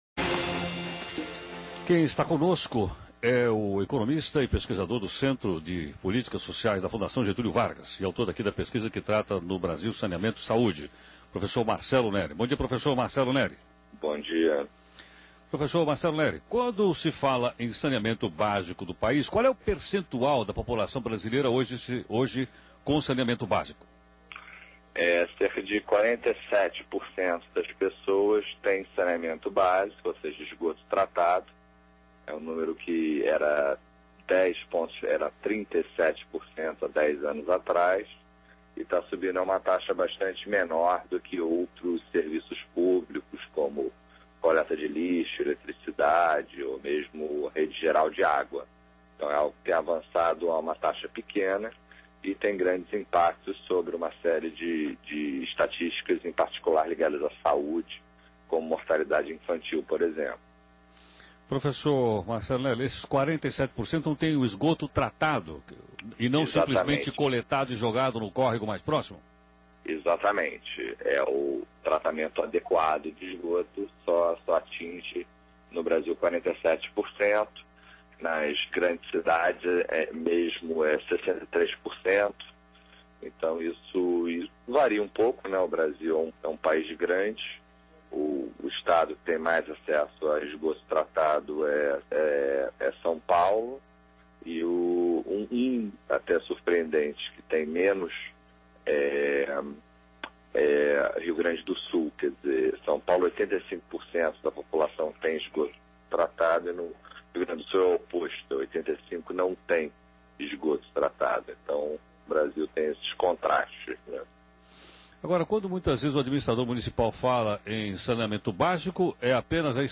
Novembro/2007 Entrevista com o Economista e Pesquisador do Centro de Politicas Sociais da FGV Marcelo Neri Meio: Rádio CBN - RJ Mídia: Rádio Temas / Subtemas Desenv.